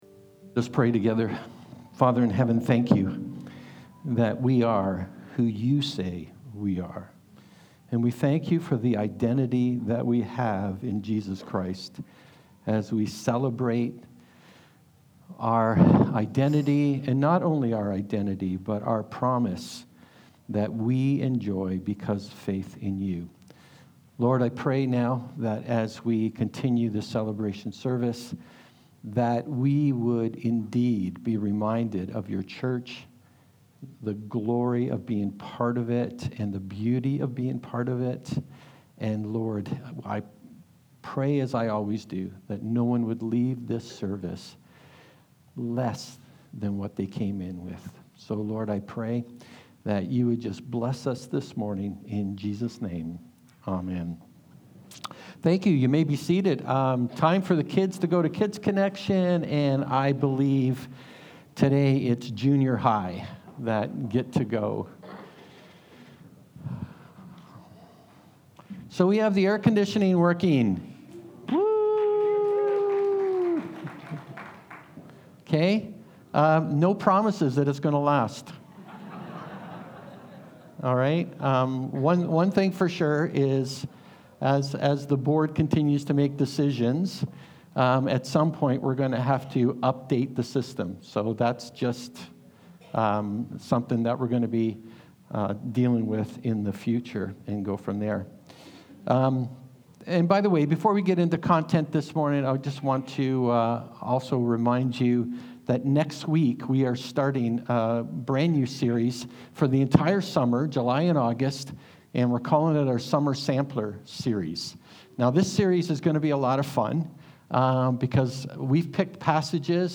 A service where we celebrate God’s wonderful blessings in our church and the incredible ways we witness lives being touched by His faithfulness!